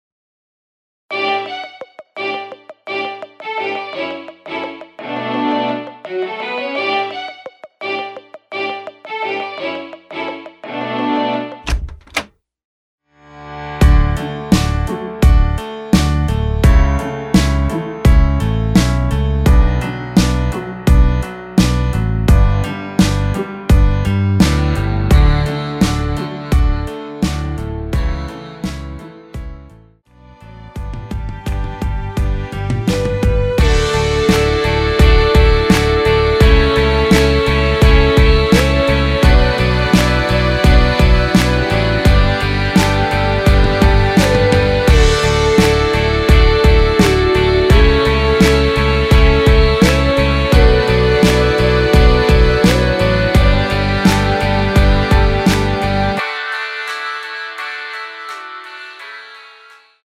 원키에서(-1)내린 MR입니다.
앞부분30초, 뒷부분30초씩 편집해서 올려 드리고 있습니다.
곡명 옆 (-1)은 반음 내림, (+1)은 반음 올림 입니다.